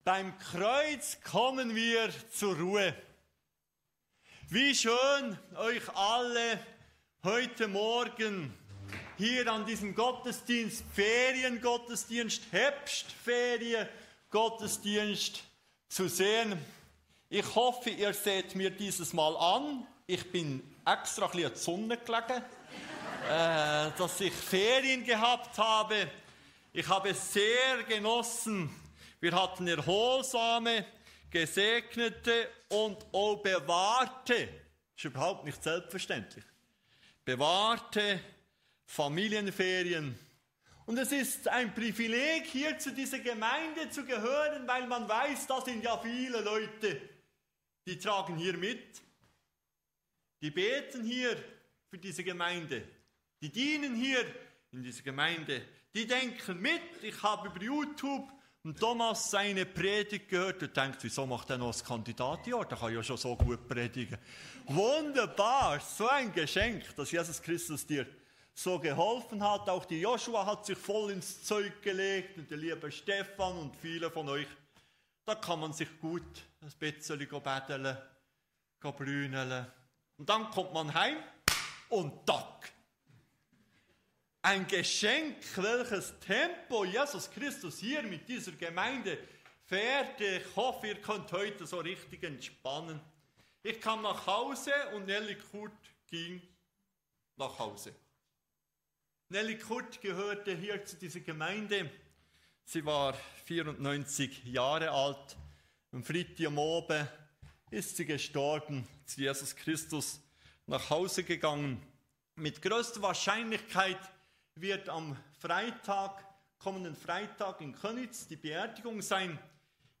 Eine Predigt über Jesaja 64: Inmitten von Not ruft Jesaja zu Gott – und wir entdecken: Gott ist nicht nur Richter, sondern unser Vater.